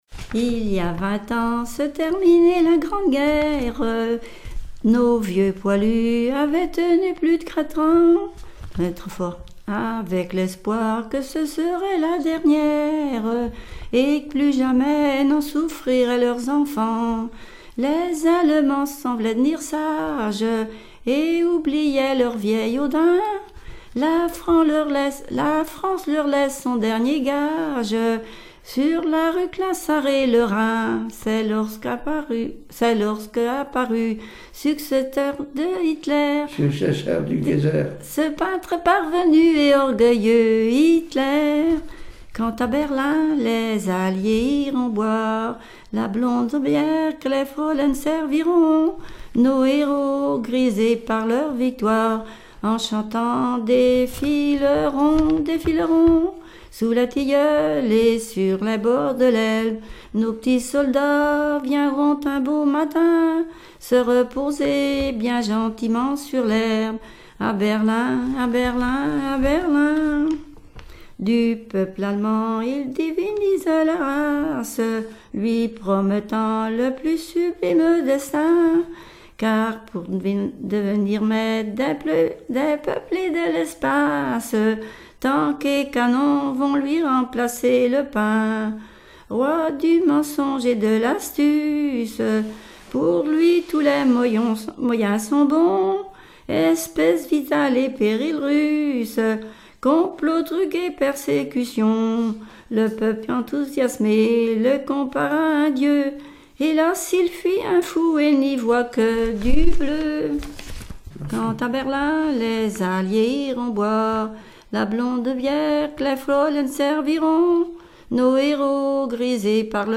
Mémoires et Patrimoines vivants - RaddO est une base de données d'archives iconographiques et sonores.
Témoignages sur les conscrits et chansons
Pièce musicale inédite